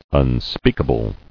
[un·speak·a·ble]